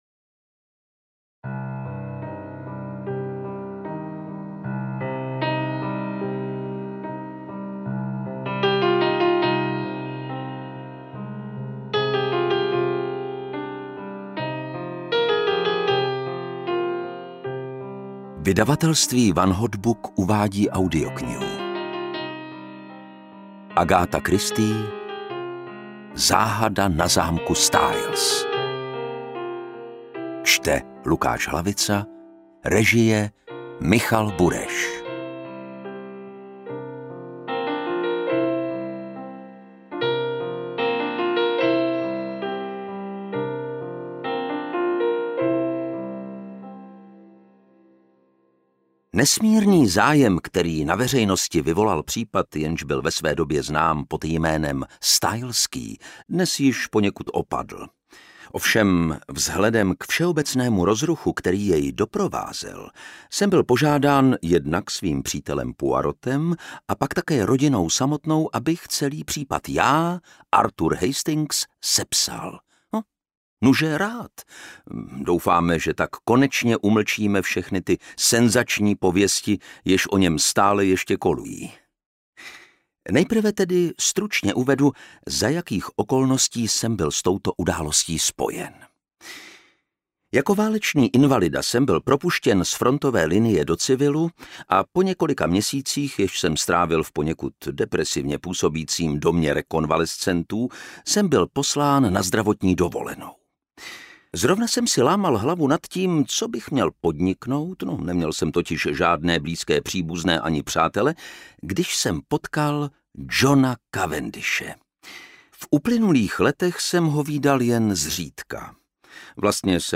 Audiobook
Read: Lukáš Hlavica